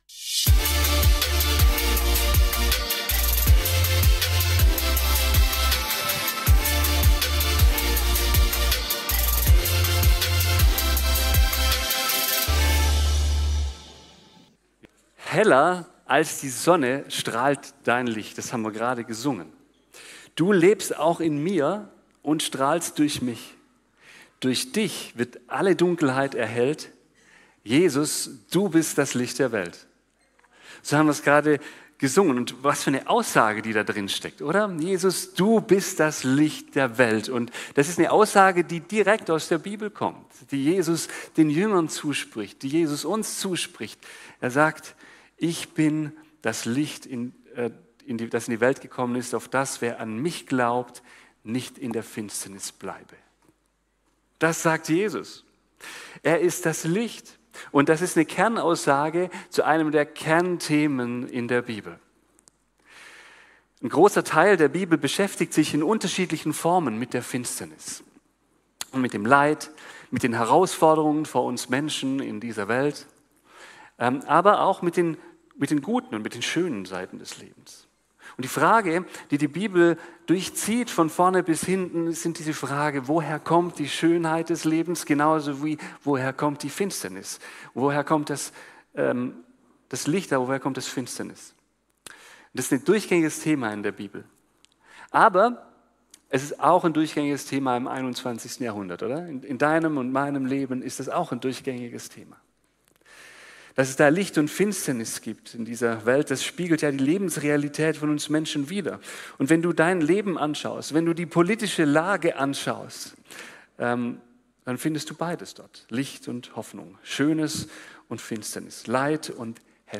Predigten online
02 FeG Lörrach & Friends 2025 Passage: Johannes 12,46 Art Des Gottesdienstes: Familiengottesdienst Dateien zum Herunterladen Gemeindebrief Themen